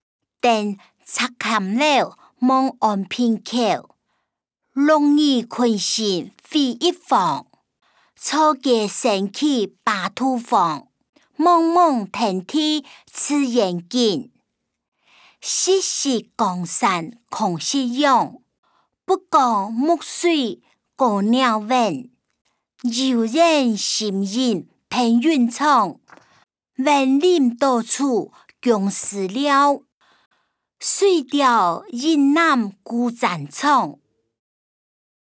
古典詩-登赤嵌樓望安平口音檔(饒平腔)